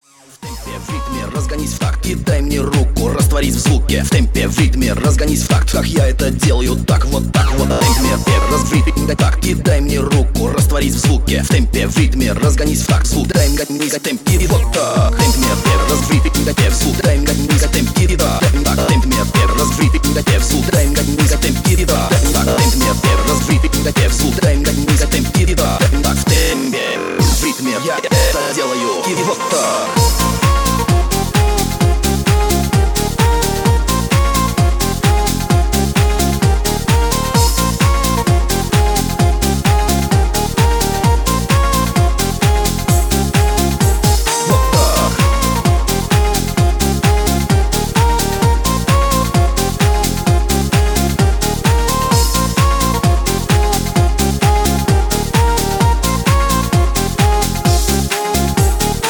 поп
ритмичные
зажигательные
веселые
Eurodance
подвижные